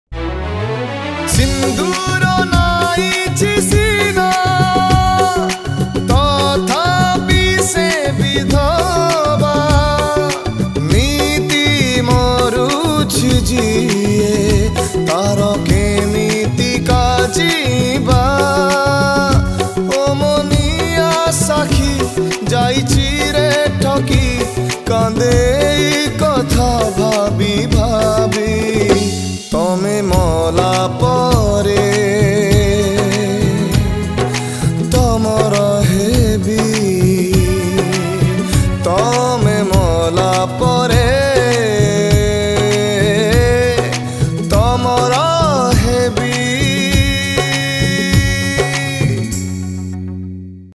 Odia Jatra Song Songs Download